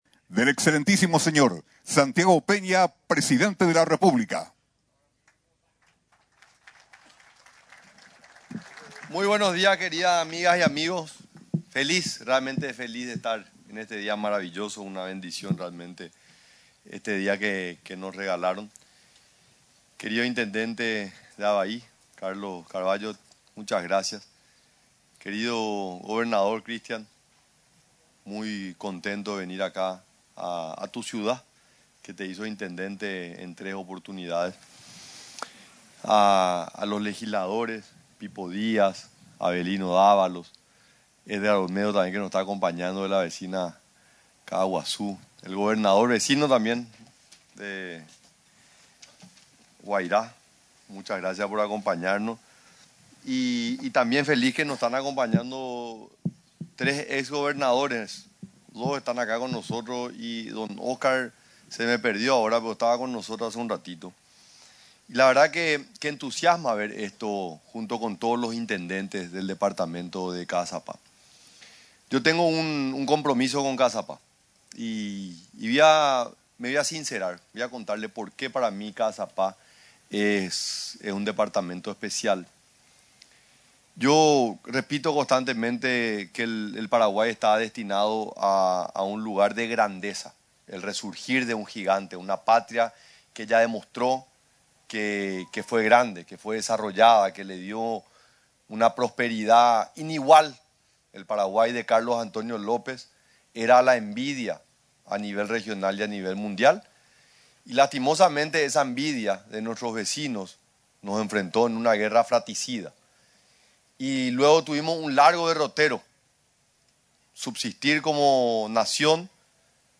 El mandatario paraguayo, durante la jornada de Gobierno en el departamento de Caazapá, en el acto de inauguración de mejoras en la Comisaría décima de Avaí, resaltó el apoyo que brinda el Estado, a través de la nueva ley, el financiamiento de capacitación, la formación de nuevos oficiales, además de triplicar la dotación del Grupo Lince por medio de inversiones sin precedentes.